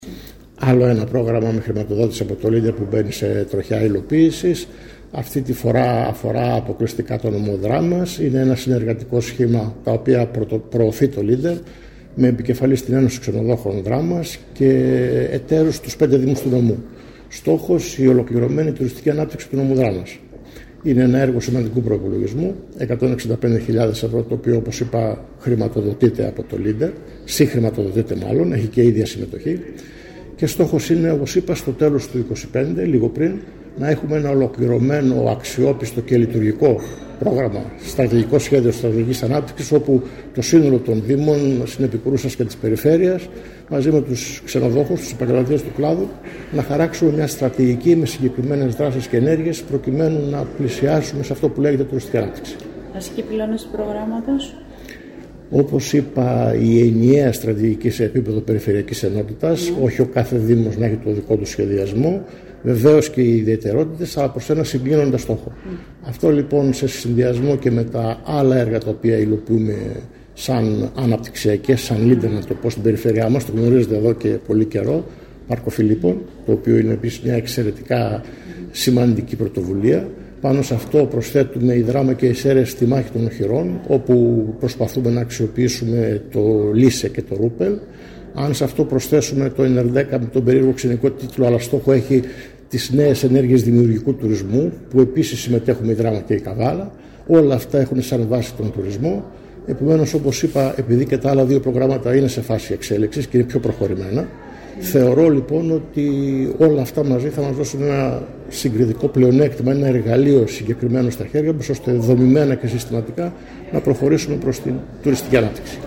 Πραγματοποιήθηκε το απόγευμα της Πέμπτης 4 Απριλίου στο ξενοδοχείο Κούρος της Δράμας, η εκδήλωση κατά την οποία ανακοινώθηκε επισήμως, η έναρξη λειτουργίας του συνεργατικού σχήματος που απαρτίζεται  από την Ένωση Ξενοδόχων Δράμας και τους 5 Δήμους του νομού, με  σκοπό  την μελέτη, καταγραφή του τουριστικού προϊόντος της Περιφερειακής Ενότητας Δράμας καθώς και την δημιουργία ενός DMMO’s οργανισμού διαχείρισης προορισμού.